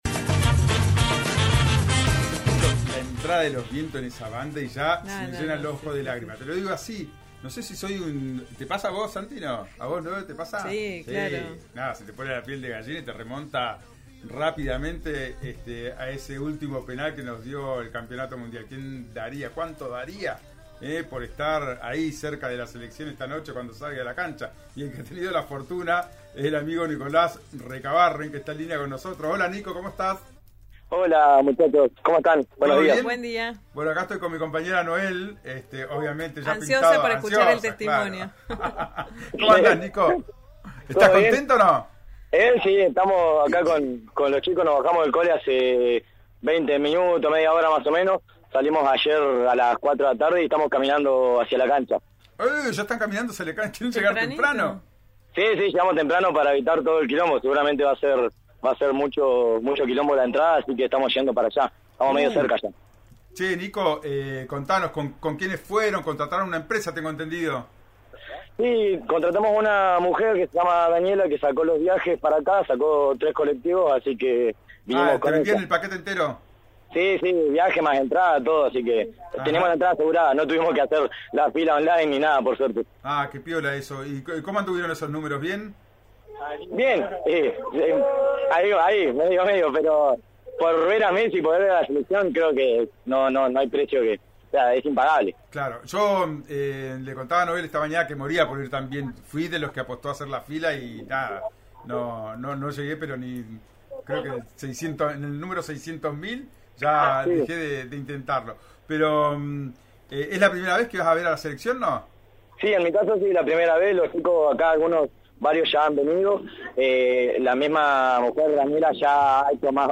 “Por ver a Messi, a la selección, no hay precio, es impagable”, había dicho este muchacho a la mañana en diálogo con RIO NEGRO RADIO antes de llegar a la Capital Federal.